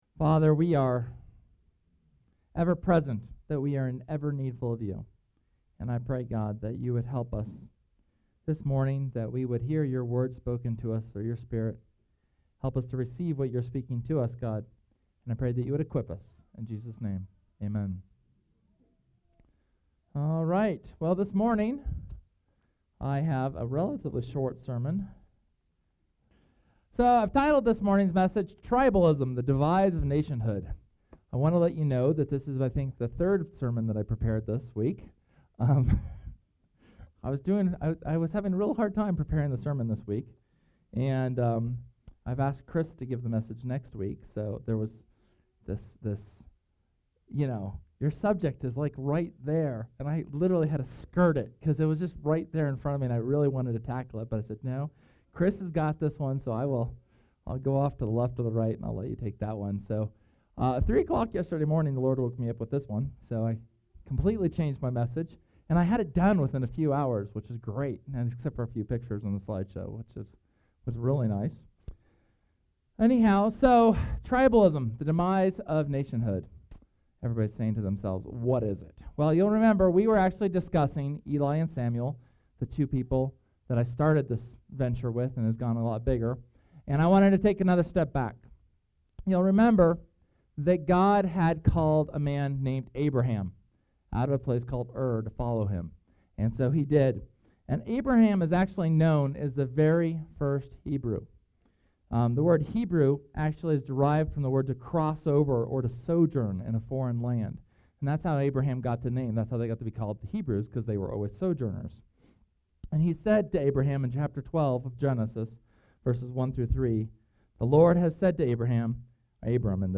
Sunday, December 9th's sermon, on the dangers of tribalism within the Church today.